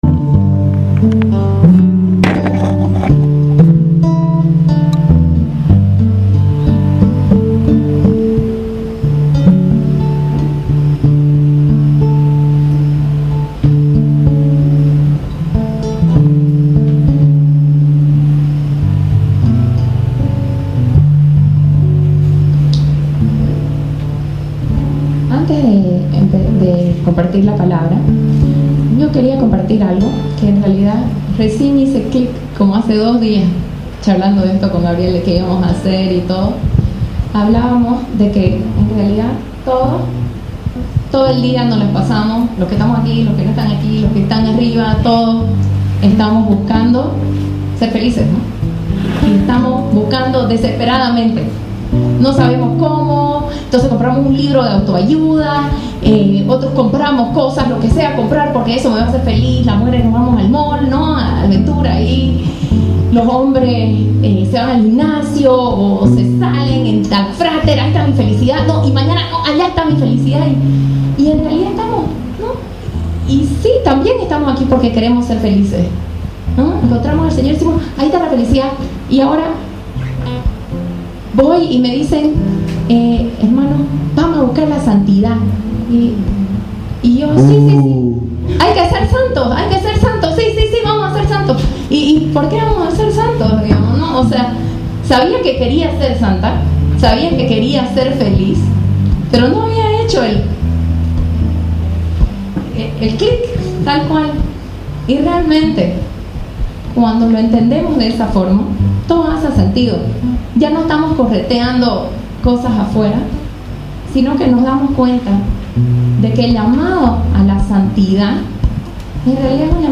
Alégrense - (Una Prédica en Oración)